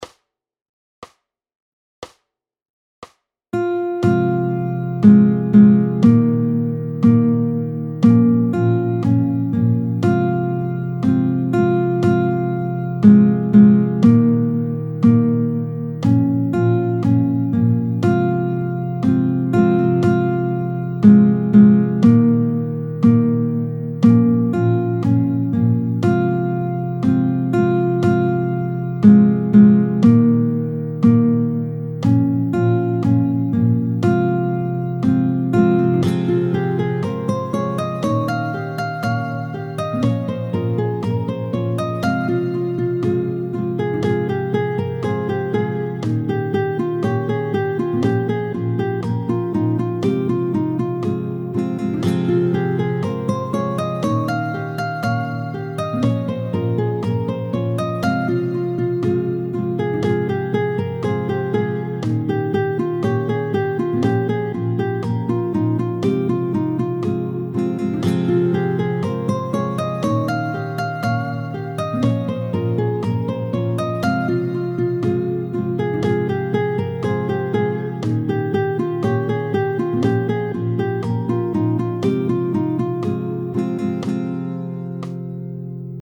La chanson est donc construite en SOL majeur.
tempo 60